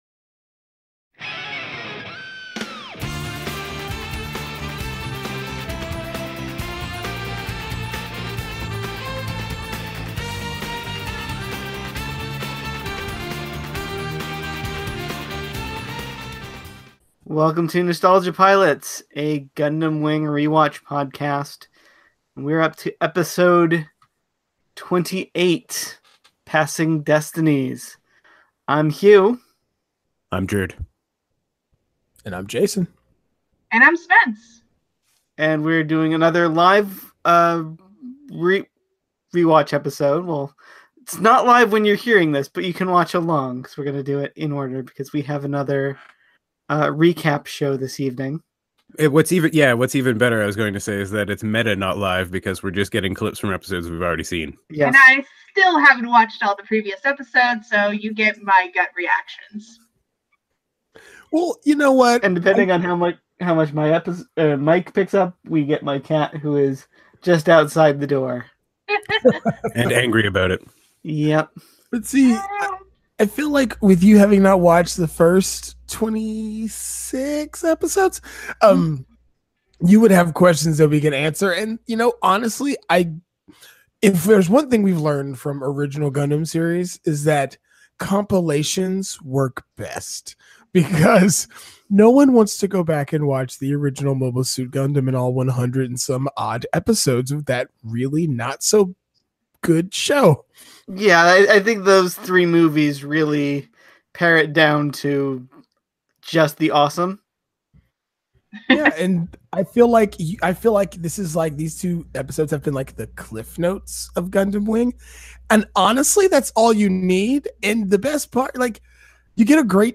Since episode 27 and 28 are Gundam Wing’s mid-series recap episodes, we’re switching to a commentary track format for the next two weeks.